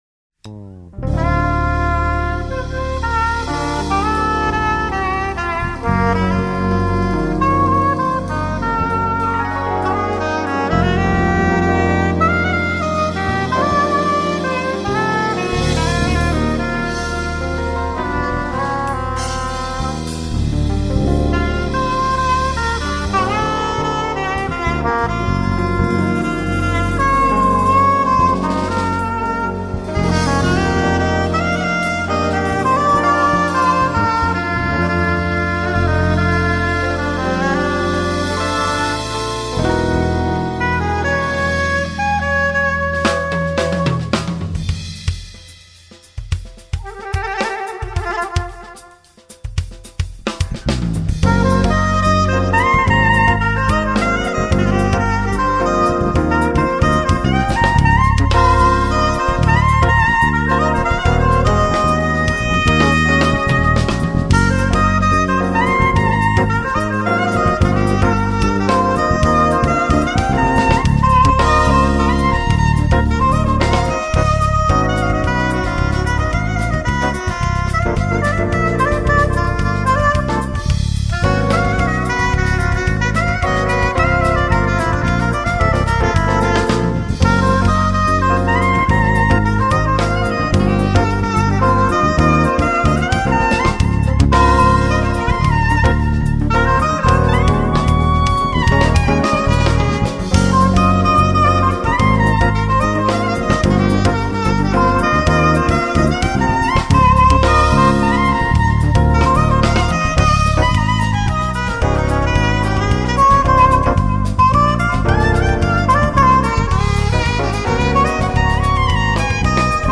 1413   07:19:00   Faixa:     Jazz